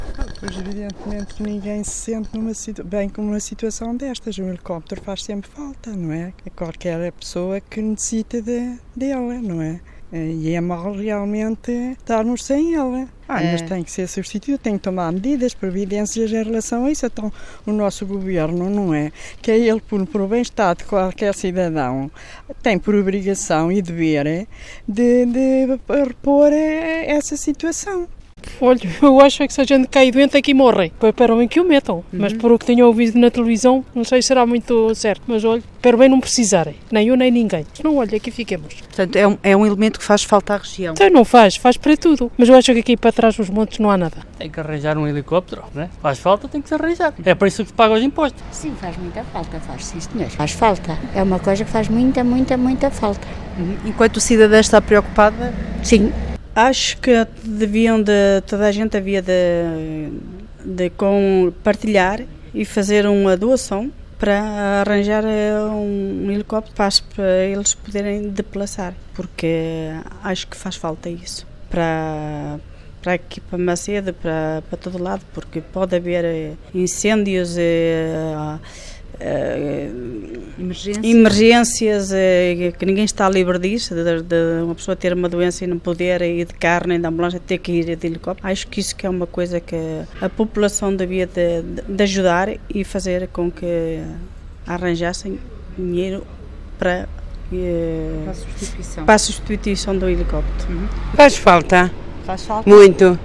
vox-pop-helicoptero.mp3